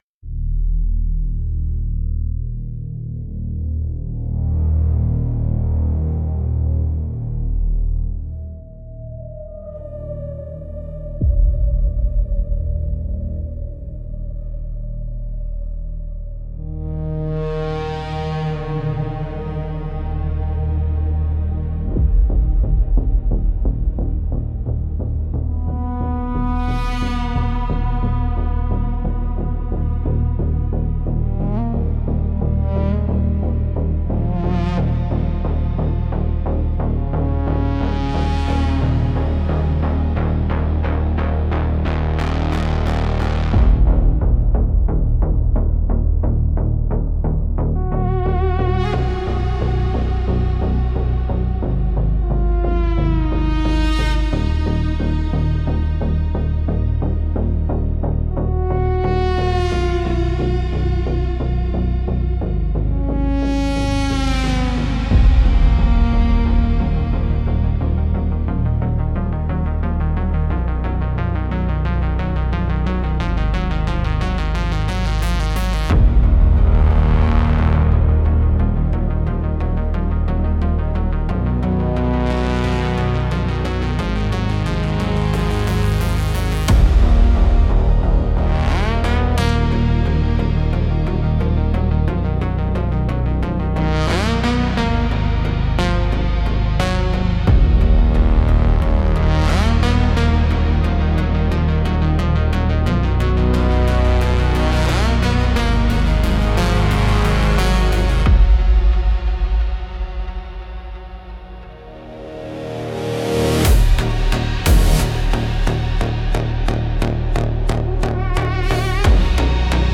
Instrumental - Wire and Shadow 4.40